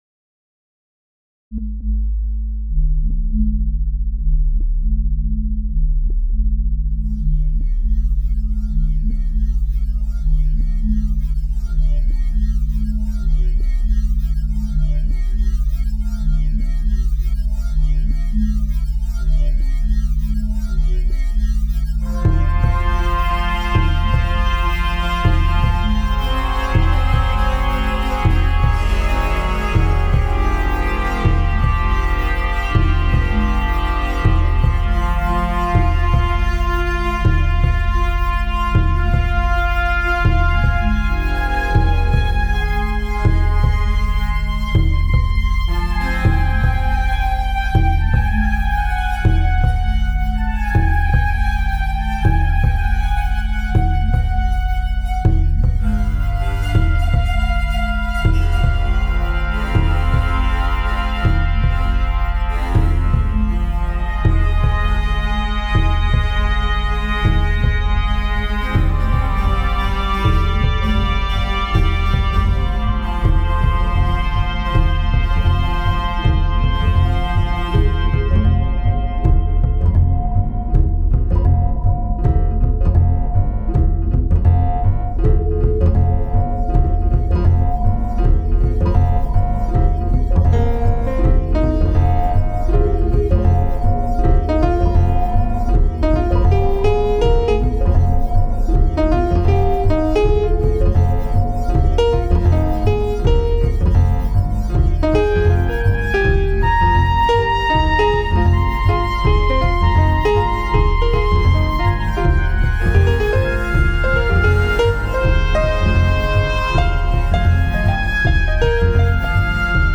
Synth- Trumpet
Synth, Piano, Bowed Piano, Bass, Drums, Percussion